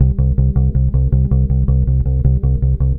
Track 16 - Bass 01.wav